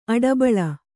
♪ aḍabaḷa